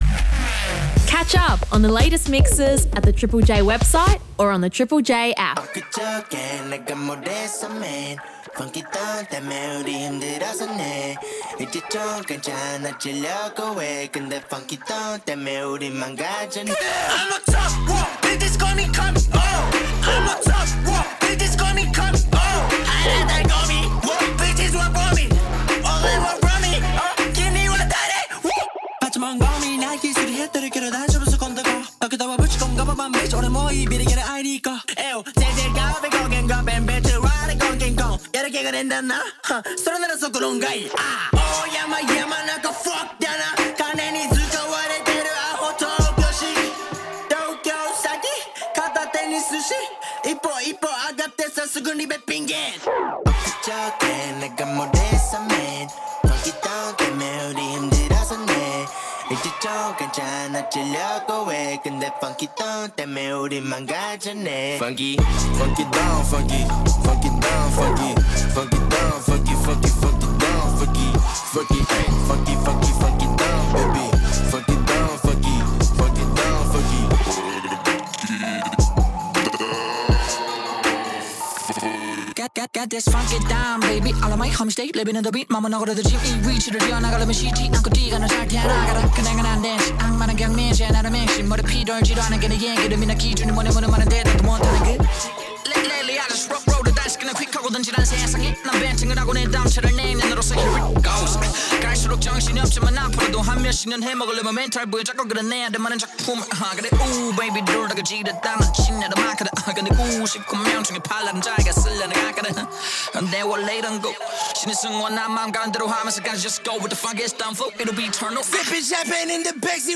Showcasing styles such as Hip Hop and Grime